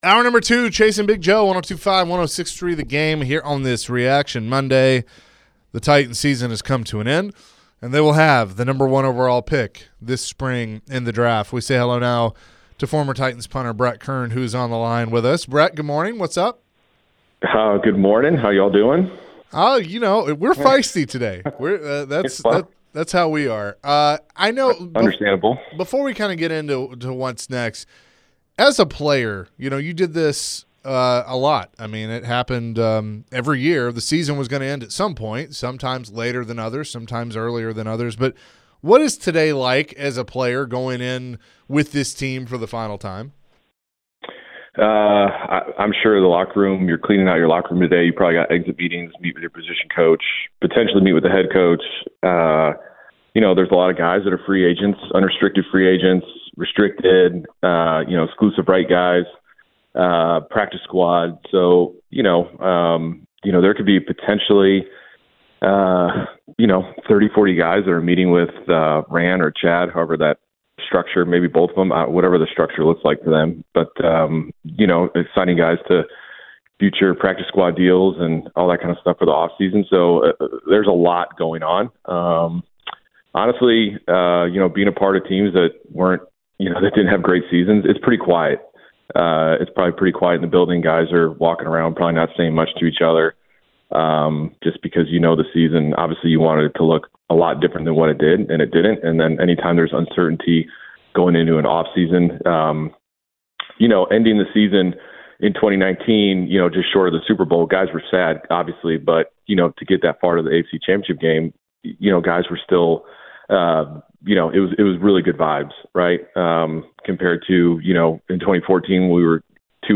Brett Kern joined the show and shared his thoughts on the Titans having the number one overall pick. Brett shared his thoughts on what the Titans should do with their pick.